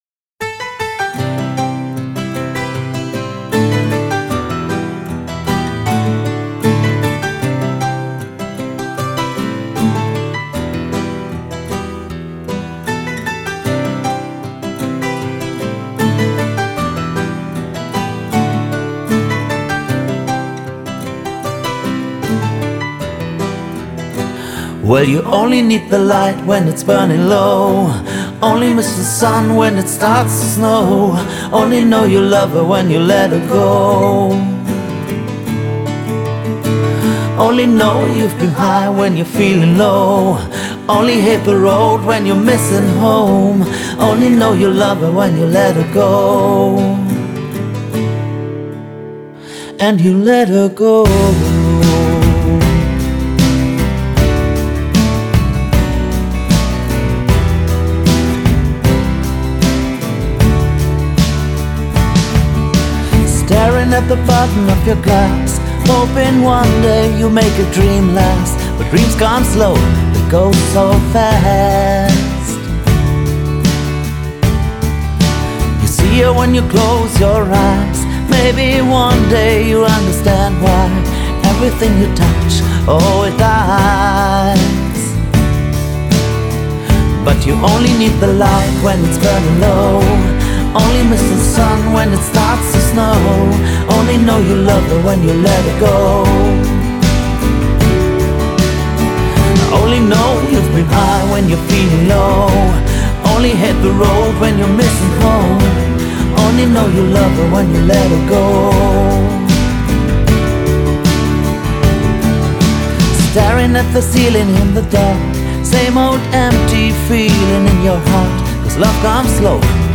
Симпатичная балладка))